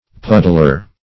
\Pud"dler\